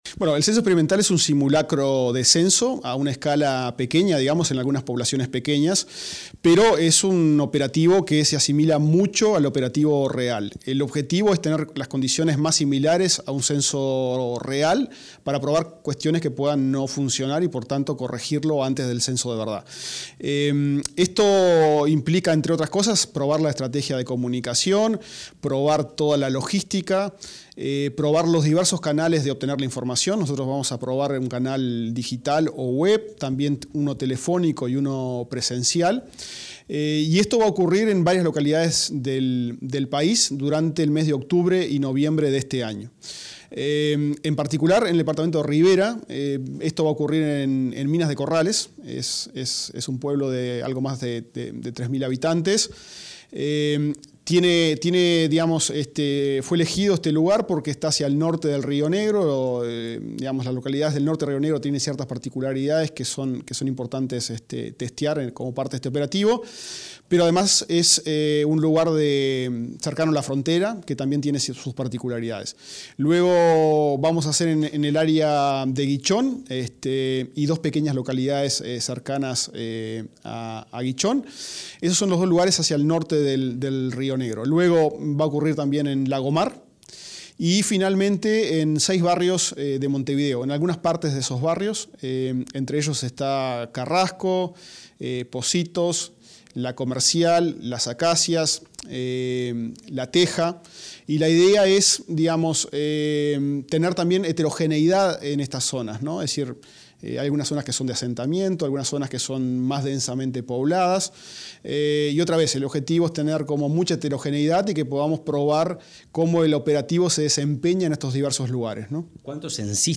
Entrevista al director del INE, Diego Aboal
El director del Instituto Nacional de Estadística (INE), Diego Aboal, dialogó con Comunicación Presidencial sobre el Censo Experimental 2022, una